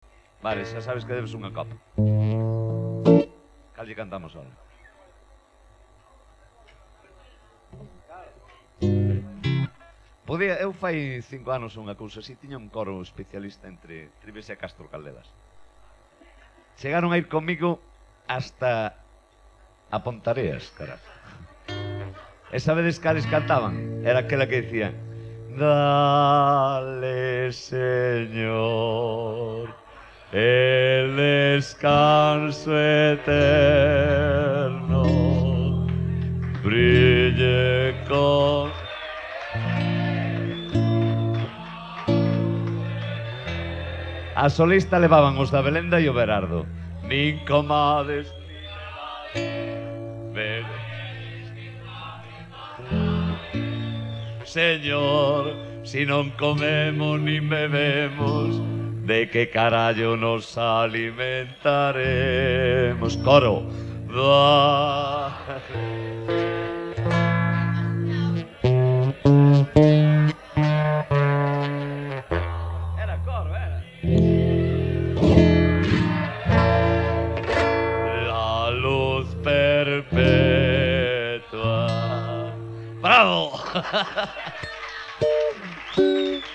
Popular Voz